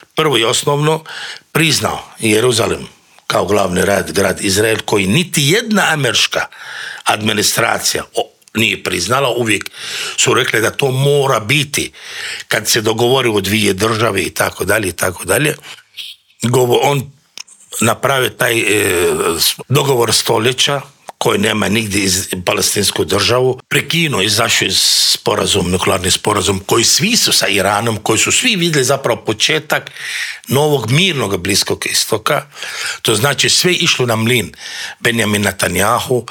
U studiju Media servisa gostovao je ratni reporter